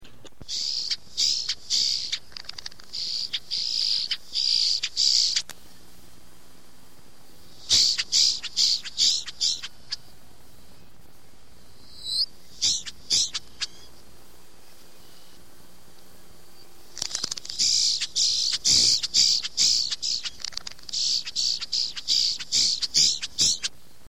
Florida Bird Sounds – Ornithology Collection
Boat-tailed Grackle
Quiscalus major  Short